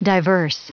Prononciation du mot diverse en anglais (fichier audio)
Prononciation du mot : diverse